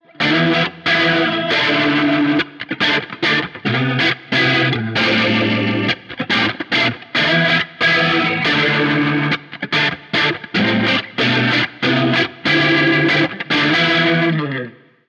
Je nach Soundvorstellung kann der Neo Instruments micro Vent 122 für einen subtilen und gefärbten Sound vor einem Overdrive-Pedal oder für einen intensiven und klaren Leslie-Effekt nach dem Overdrive platziert werden.
Der Leslie Sound des kleinen Pedals wirkt sehr dreidimensional und hat viel Tiefe.
Klangbeispiele: Neo Instruments micro Vent 122 Test
Vent → Overdrive, Fast Speed, Blend 2 Uhr, Distance 1 Uhr